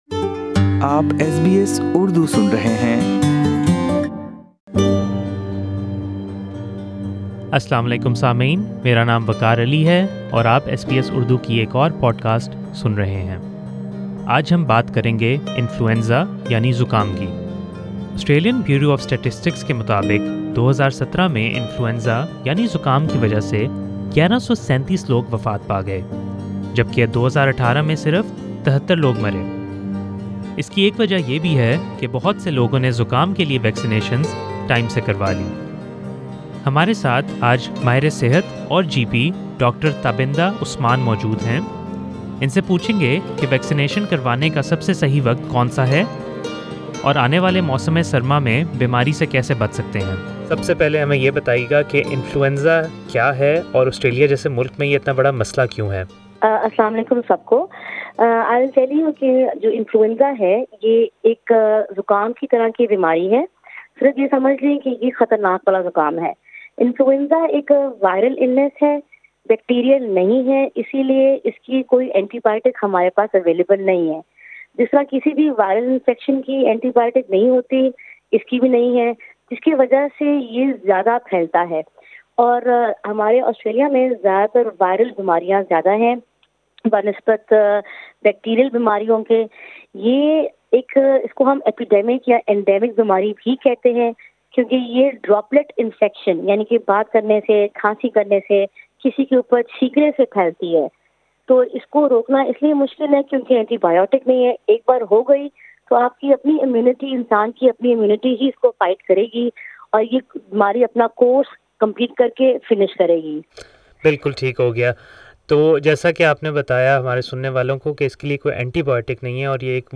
SBS Urdu talked to a health expert to find out when is the best time to get the flu vaccine.